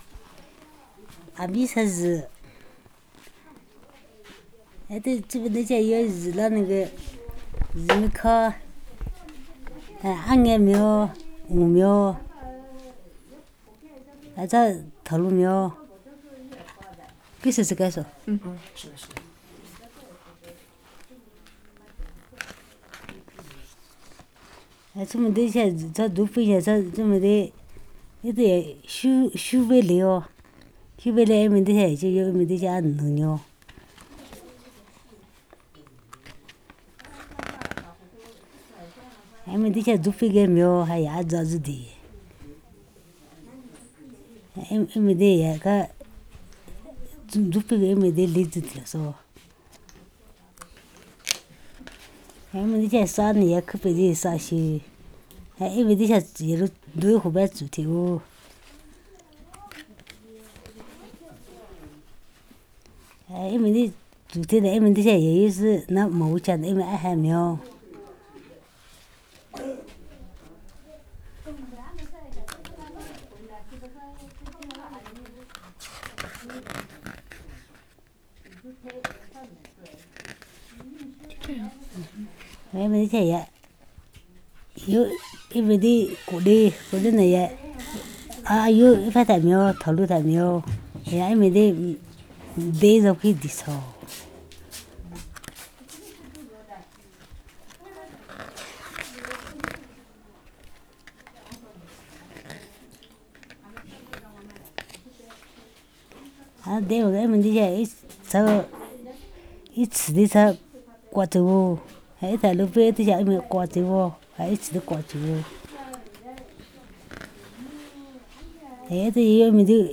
digital wav file recorded at 44kHz/16 bit on Zoom H4n solid state recorder
China, Yunnan Province, Chuxiong Yi Autonomous Prefecture, Wuding County, Gubai Administration, Yangjiacun Village